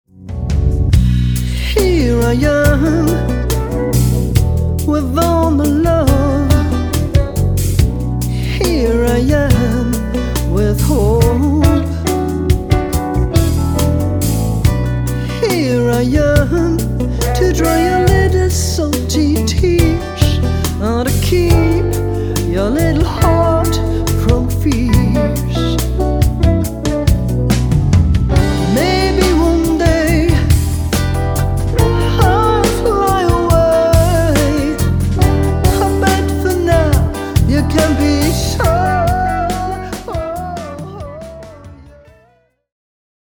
Blues Jazz Soul
Keys, Organ, Vocals
Guitar
Bass
Drums